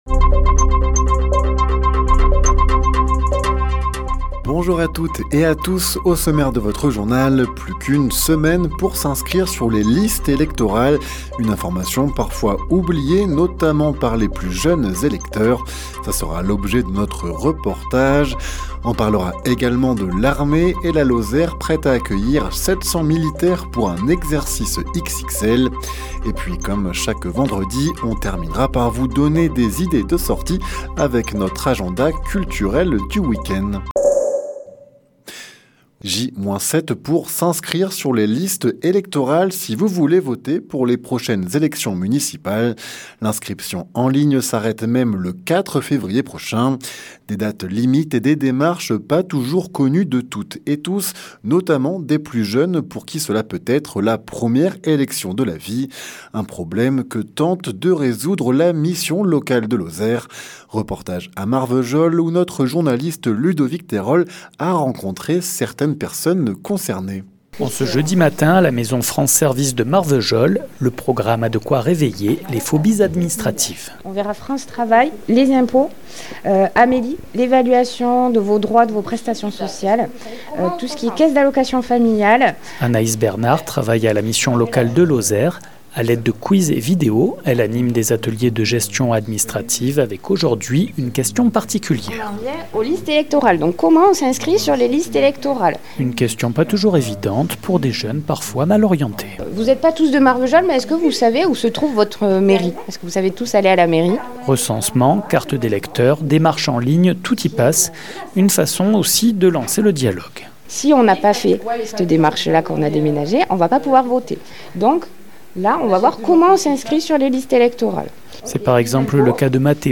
Les informations locales
Le journal sur 48FM